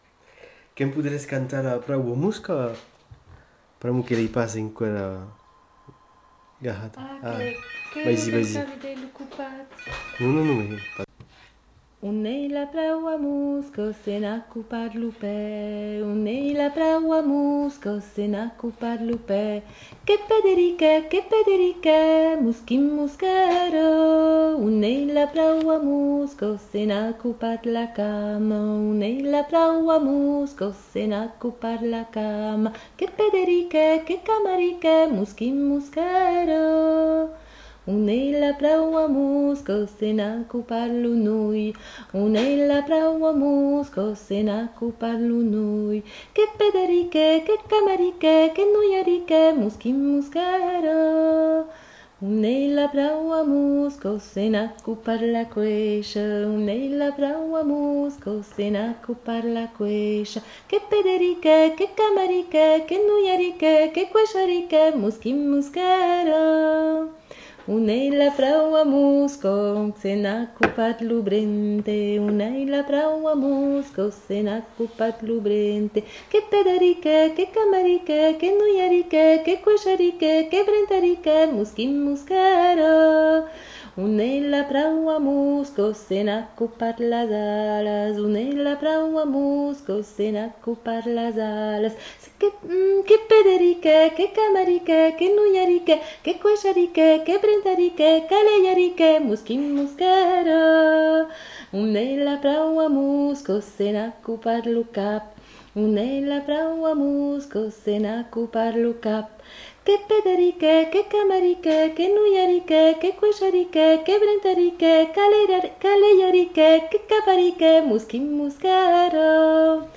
Aire culturelle : Bigorre
Lieu : Ayzac-Ost
Genre : chant
Effectif : 1
Type de voix : voix de femme
Production du son : chanté
Notes consultables : Suivi de l'interprétation lente d'un couplet de ce chant.